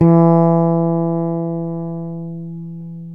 Index of /90_sSampleCDs/Roland L-CD701/BS _E.Bass 3/BS _PNA Fretless